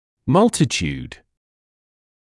[‘mʌltɪt(j)uːd][‘малтит(й)уːд]множество, большое число